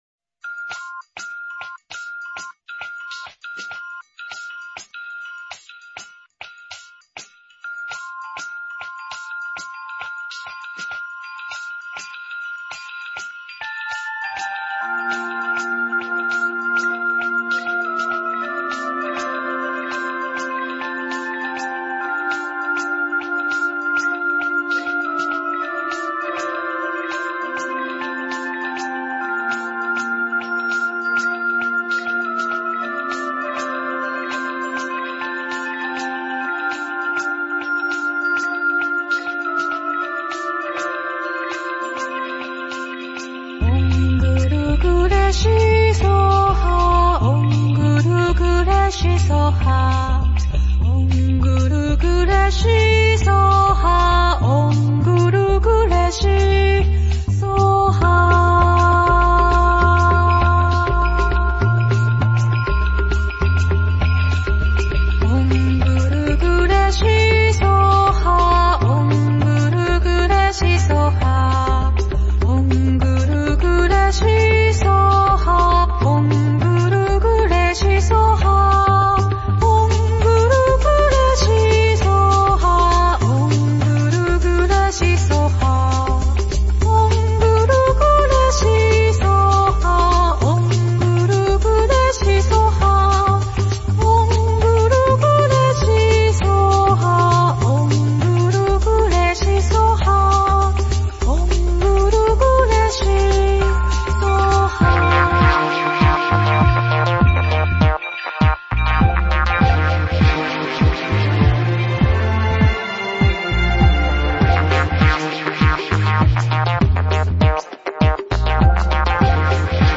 佛音 真言 佛教音乐 返回列表 上一篇： 陀罗尼(梵文